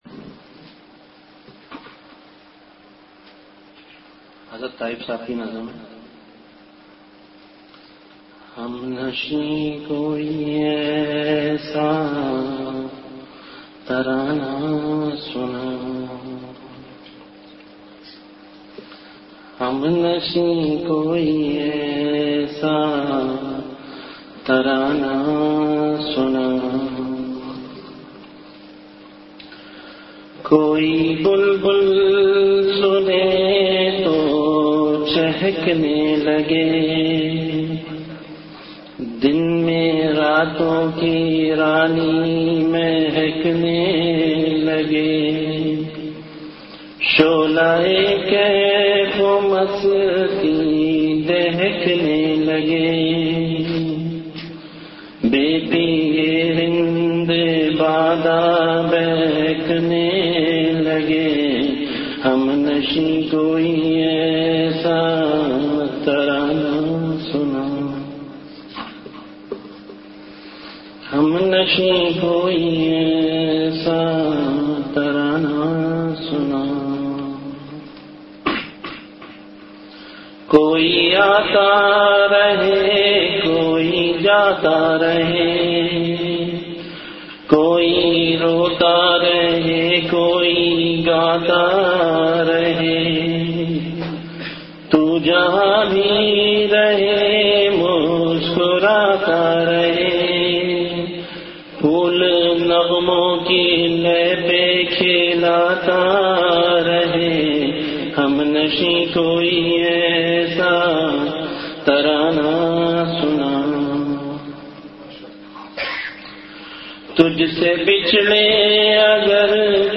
Majlis-e-Zikr
Event / Time After Isha Prayer